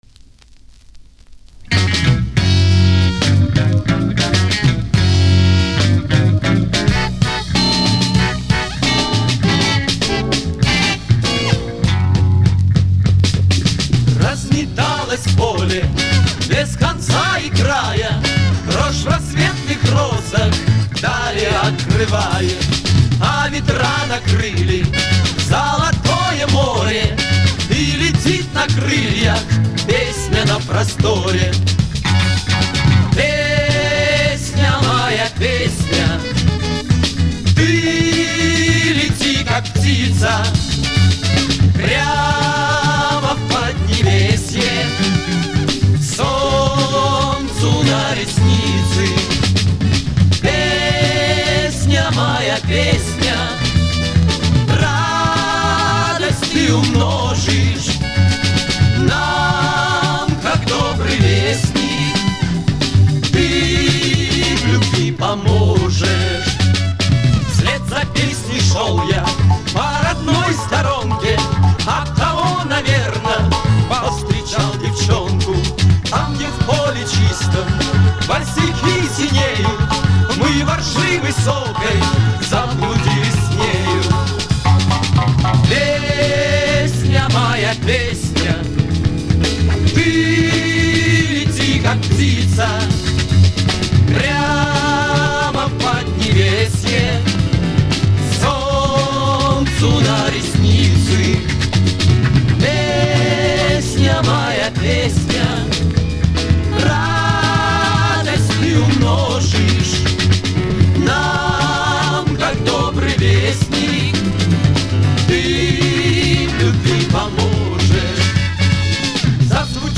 гибкая грампластинка